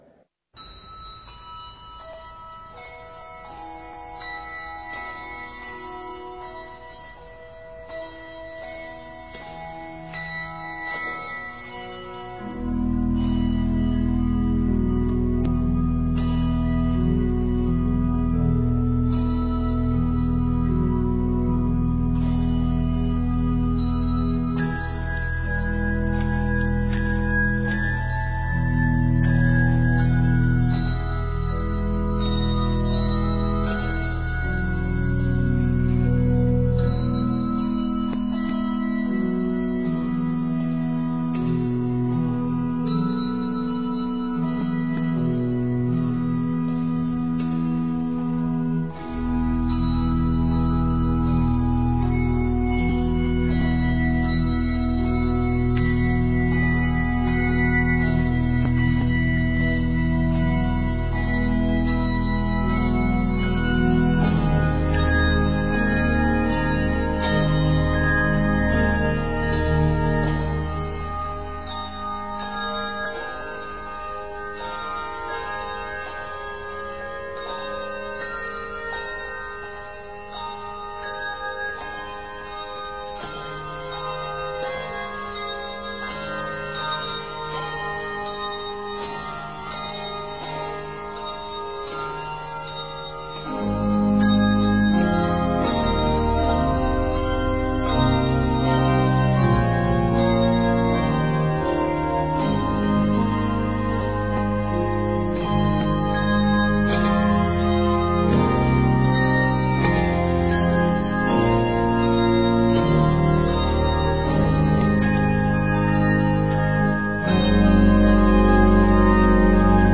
Handbells and organ are required in this arrangement
Octaves: 3-5